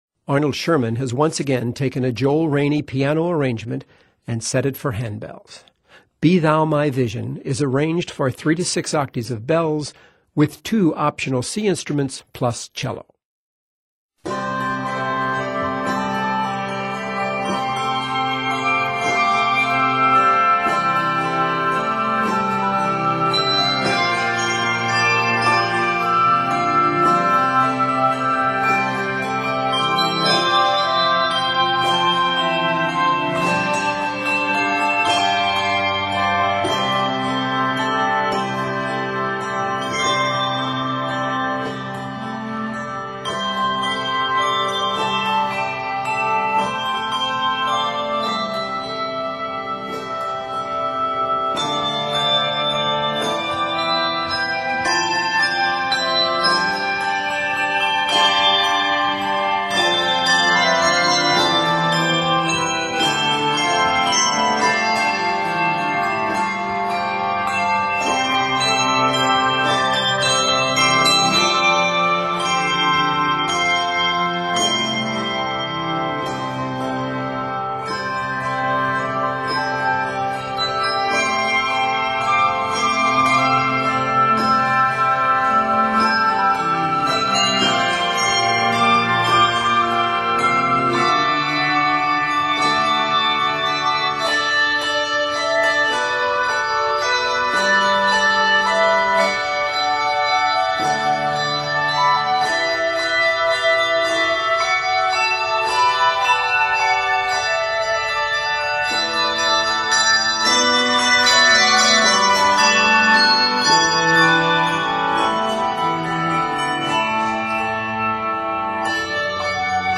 gently flowing Celtic melody
for handbells and 2 optional C instruments, plus cello